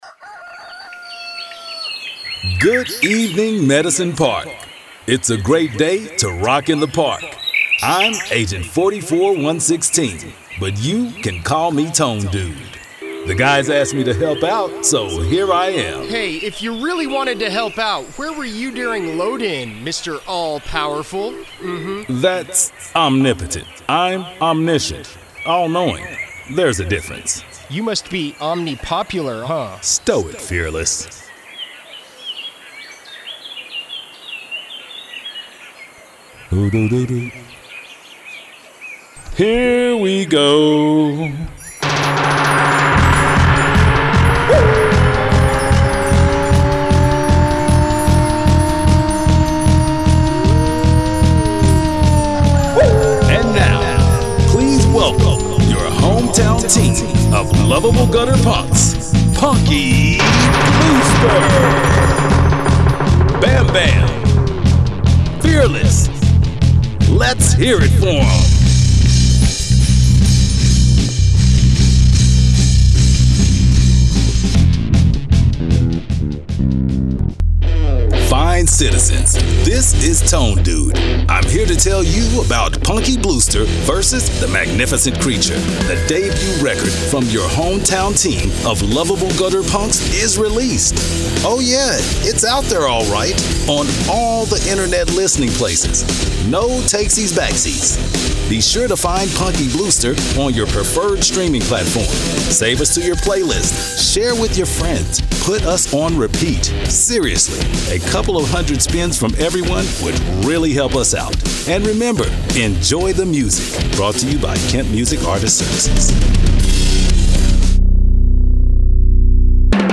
Male
English (North American)
Adult (30-50), Older Sound (50+)
With ability to deliver from the confident or reserved guy next door appeal over to a deep, authoritative, godly resonance that will breathe life into any VO project.
Main Demo
Character / Cartoon
A Smooth Deep Display 4 Radio
All our voice actors have professional broadcast quality recording studios.